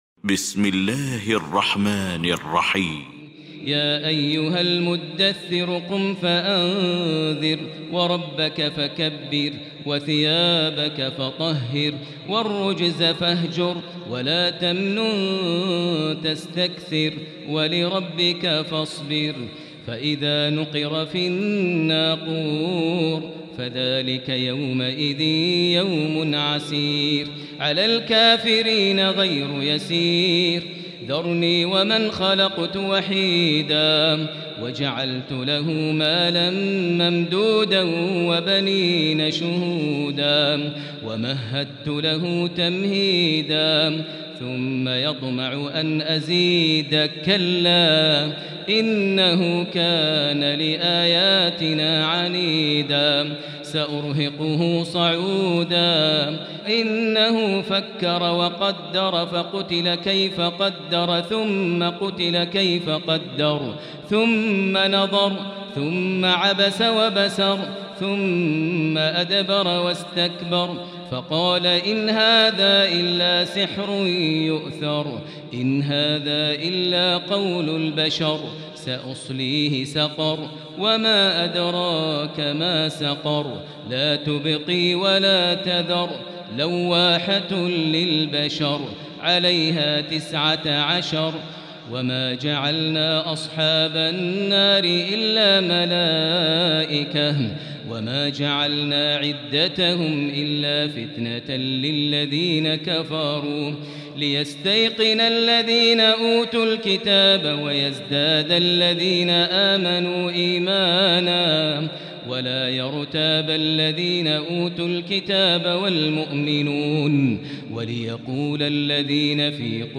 المكان: المسجد الحرام الشيخ: فضيلة الشيخ ماهر المعيقلي فضيلة الشيخ ماهر المعيقلي المدثر